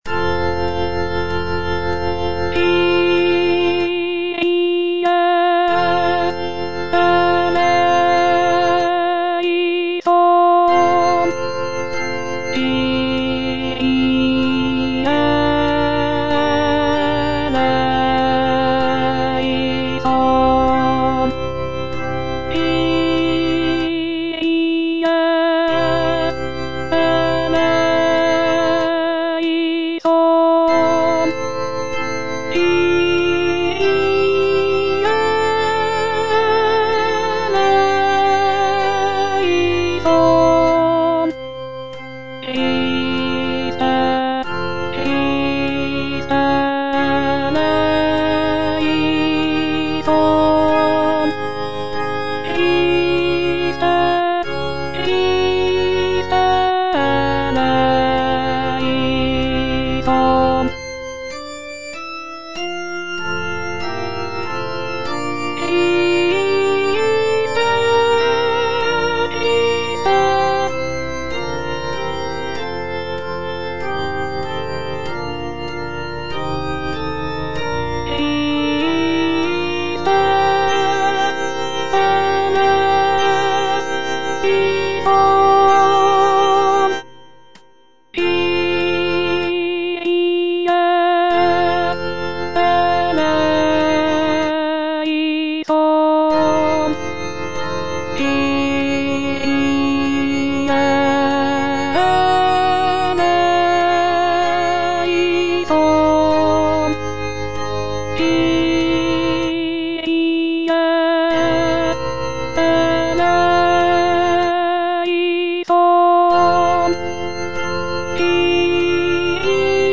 Alto (Voice with metronome